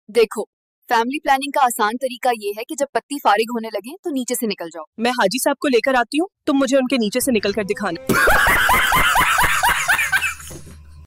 Doctor Patient Funny Scene
You Just Search Sound Effects And Download. tiktok funny sound hahaha Download Sound Effect Home